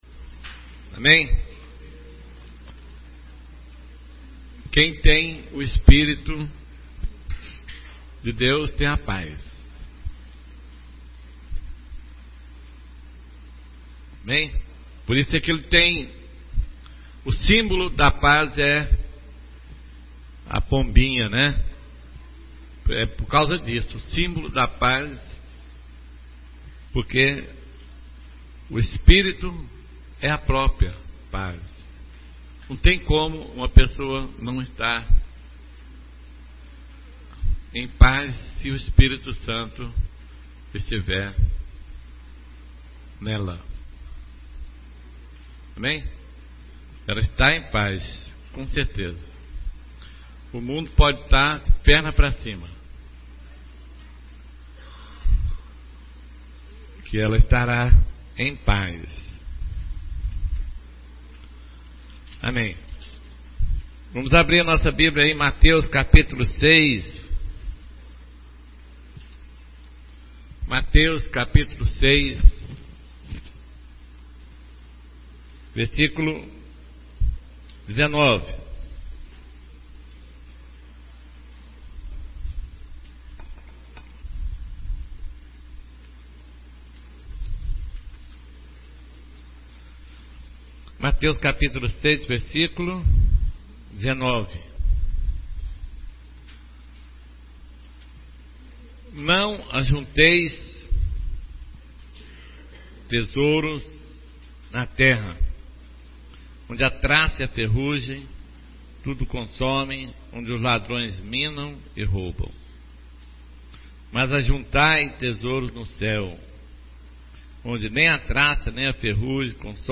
Resumo reunião domingo de manhã